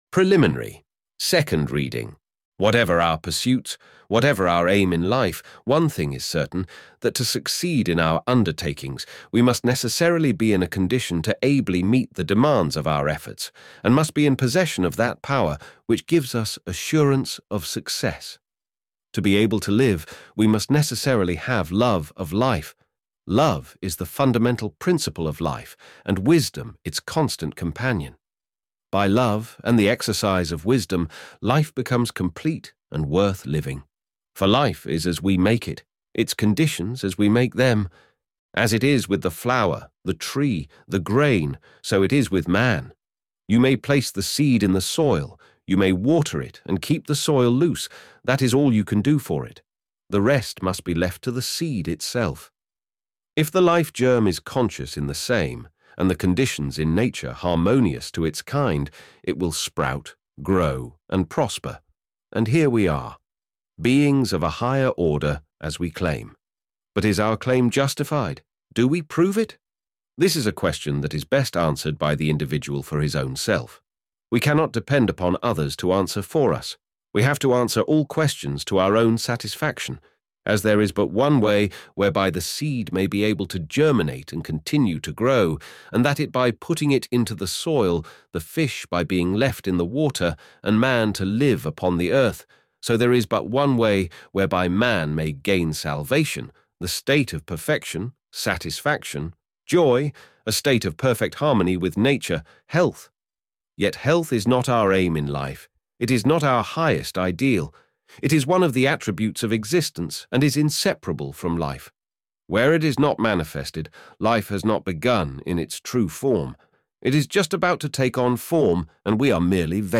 PRELIMINARY - SECOND READING